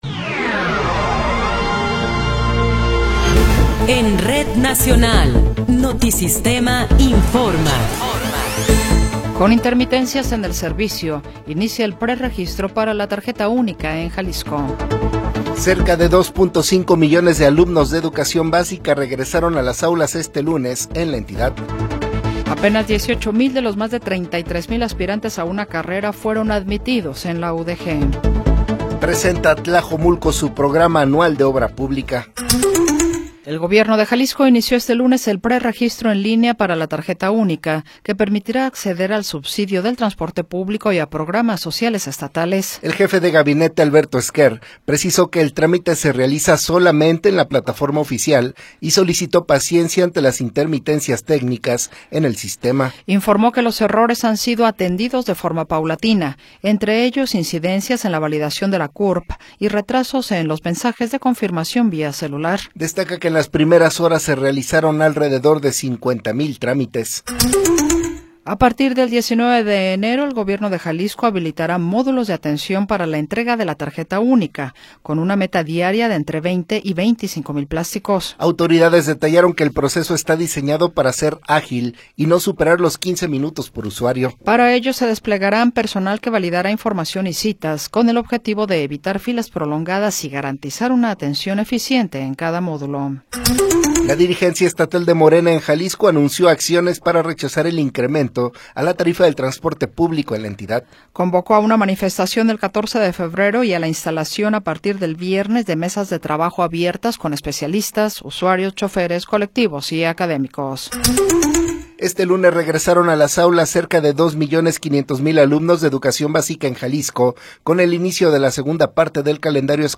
Noticiero 20 hrs. – 12 de Enero de 2026
Resumen informativo Notisistema, la mejor y más completa información cada hora en la hora.